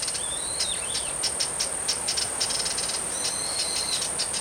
なお、観察中にこのムシクイ類が移動する方向からウグイスの警戒声と思われるけたたましい声が聞こえてきたが、このムシクイ類が発した声とは考えられない・・・・？だろうなぁ。